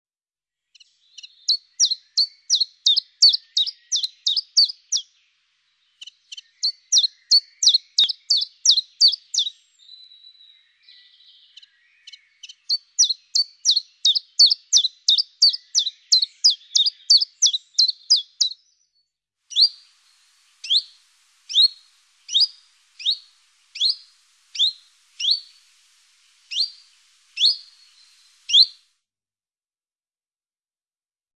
Pouillot veloce.mp3